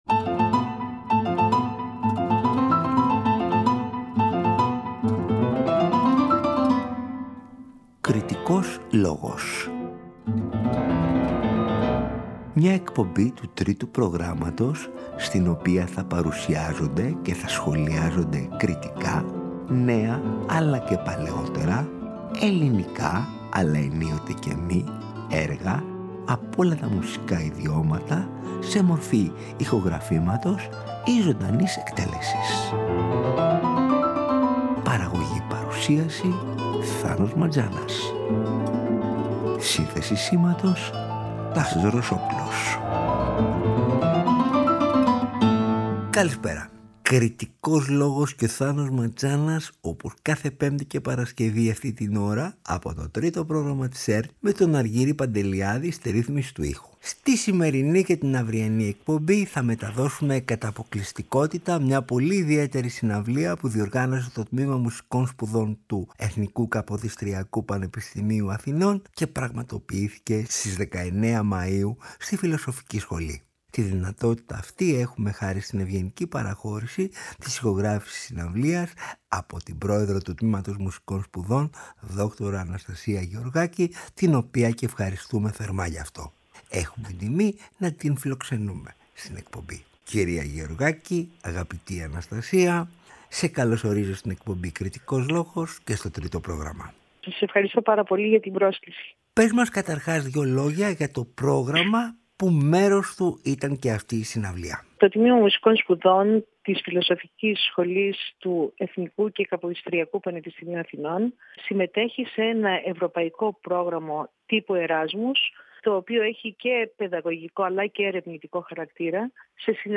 Η συναυλία έλαβε χώρα στις 19 Μάϊου στην Φιλοσοφική Σχολή του ΕΚΠΑ.
για φωνή και πιάνο
για κλαρινέτο και πιάνο
έργο ηλεκτροακουστικής μουσικής
κλασική κιθάρα